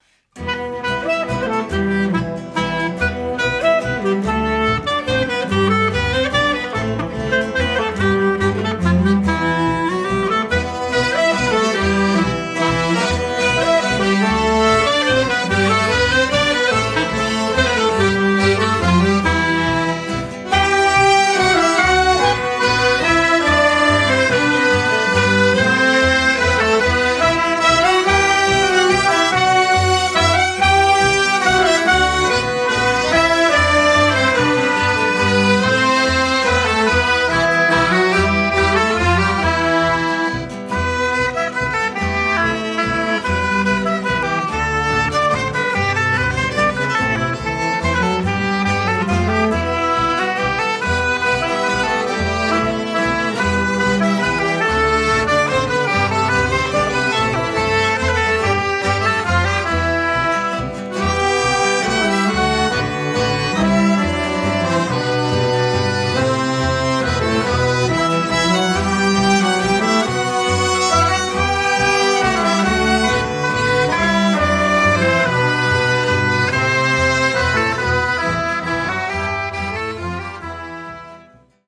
(Traditional Music)